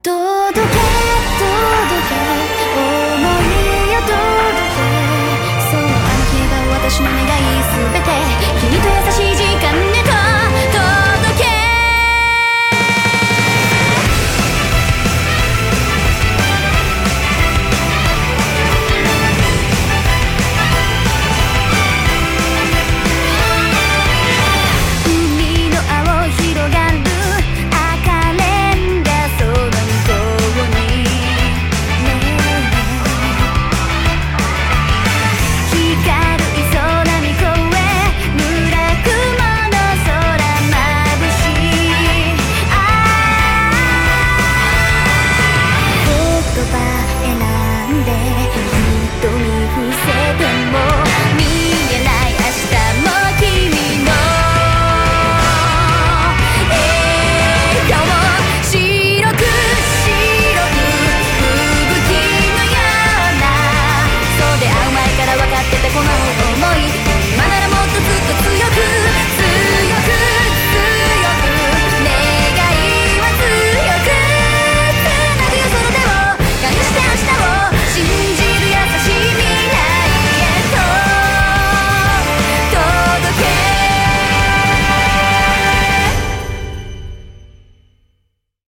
BPM90-180
Audio QualityCut From Video